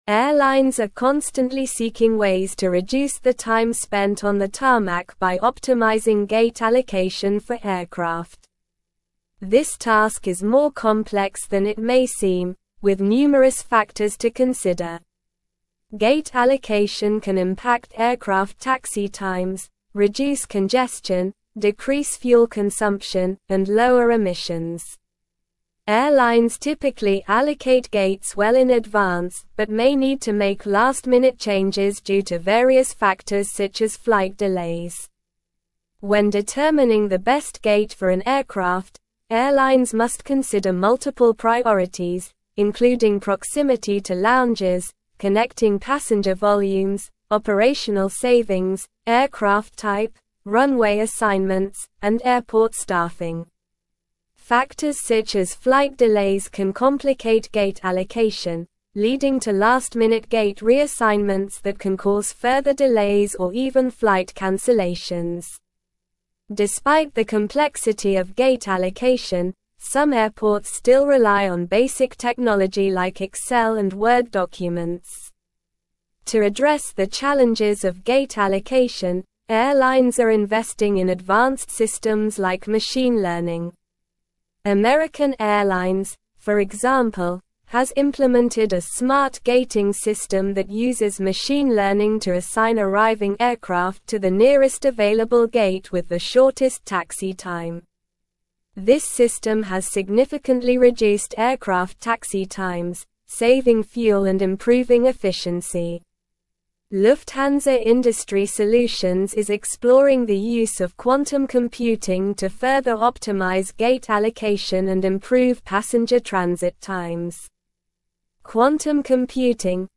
Slow
English-Newsroom-Advanced-SLOW-Reading-Efficient-Gate-Allocation-in-Airports-Innovations-and-Benefits.mp3